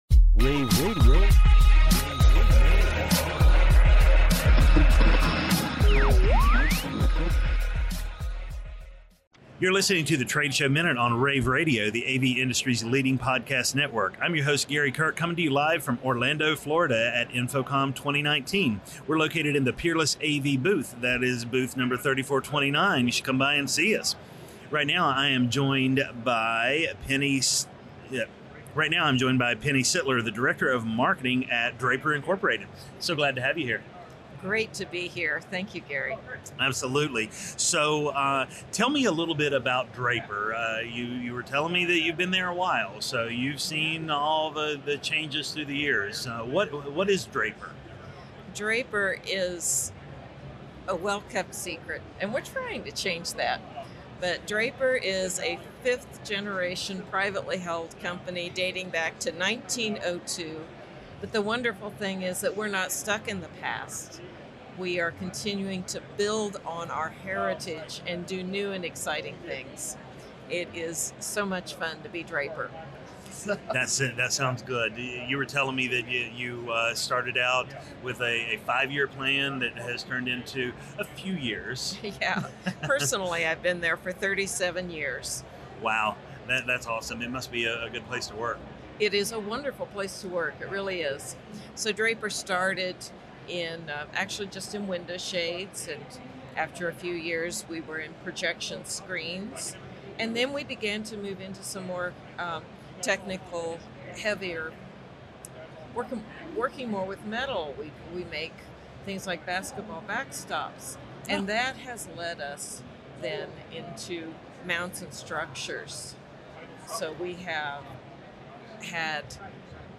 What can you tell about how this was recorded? June 13, 2019 - InfoComm, InfoComm Radio, Radio, The Trade Show Minute,